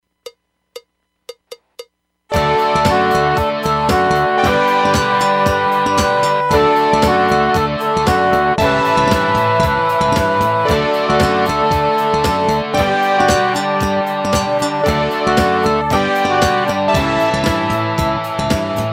This is an action song for learning about Australia.
Instrumental mp3 Song Track